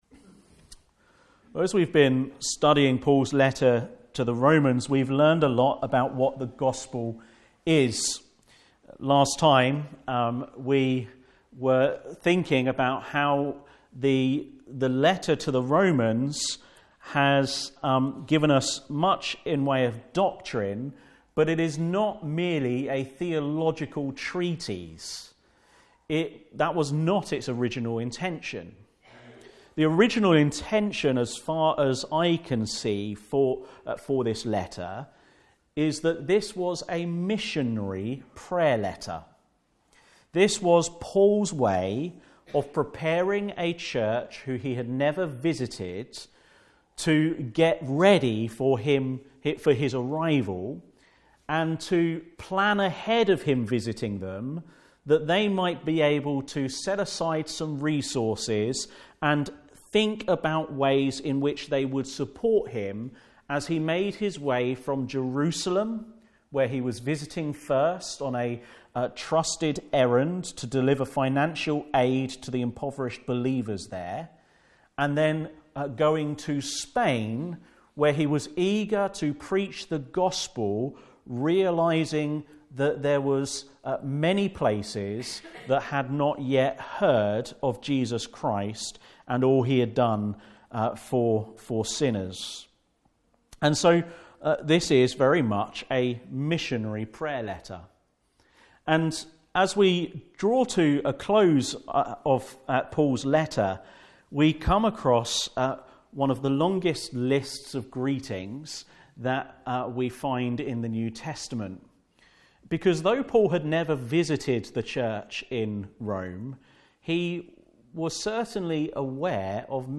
5-10 Service Type: Afternoon Service Special Service Did Jesus Really Rise From the Dead?